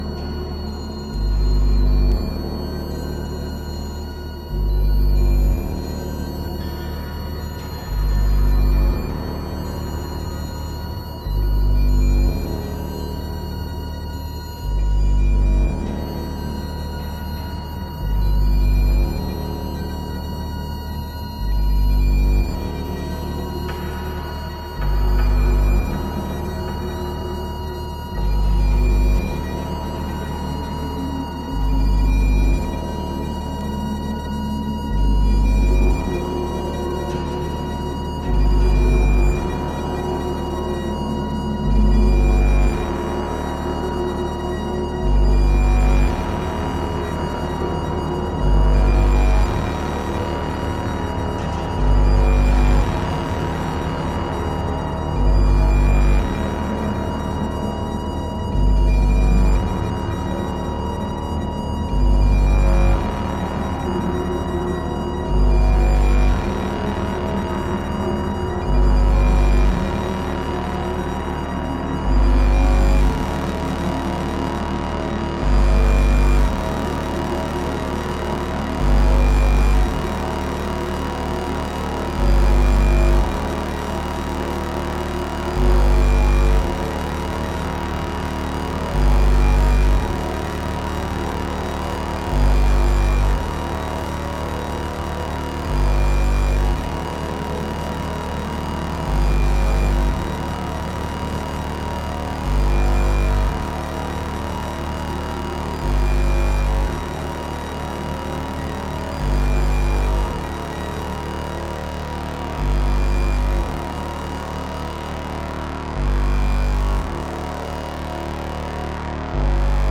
IDM/Electronica